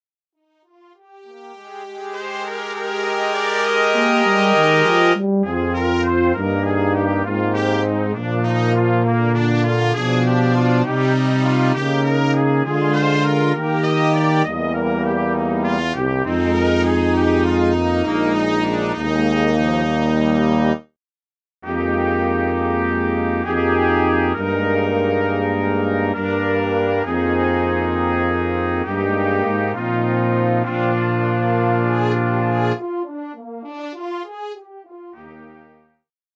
for Brass Quintet
this version features a re-harmonization of the melody